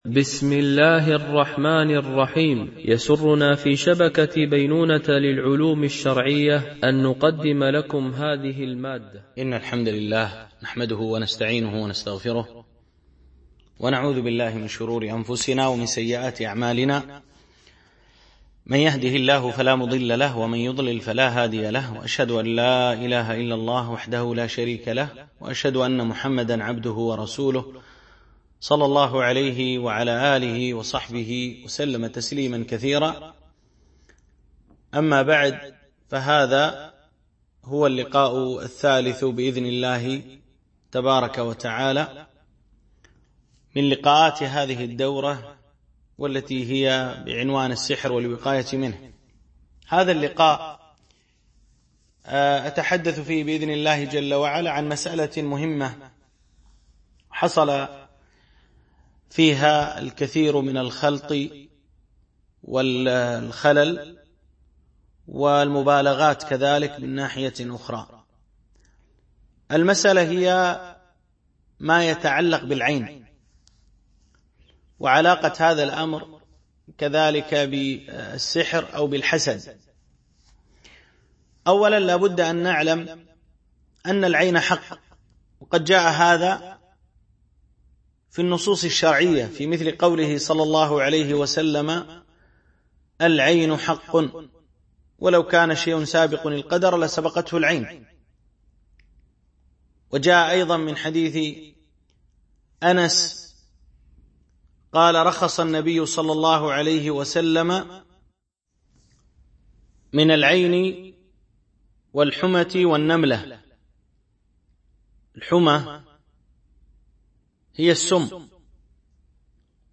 السحر والوقاية منه ـ الدرس 3 ( العلاج من العين وواجب المسلم في ذلك )